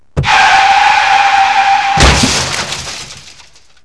Click Here    The sound of screeching brakes and the crash
brakes-crash.wav